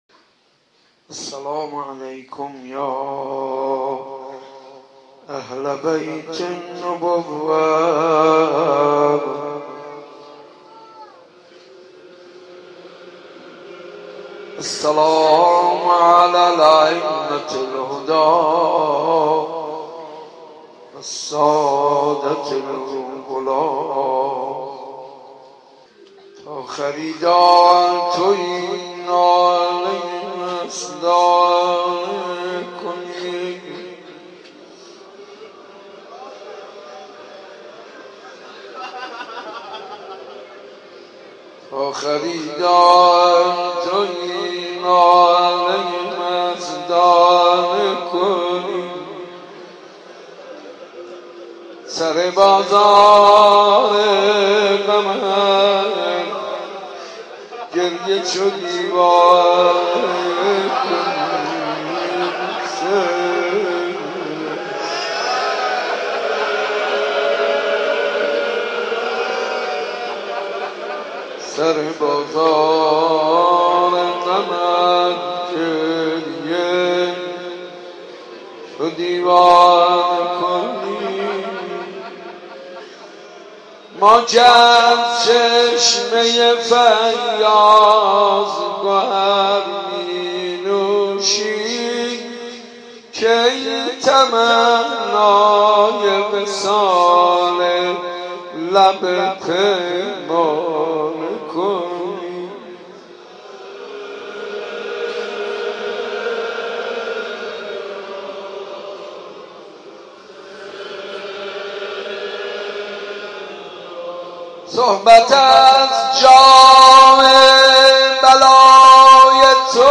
شب 23 محرم 91 حاج منصور ارضی بیت الزهراء سلام الله علیها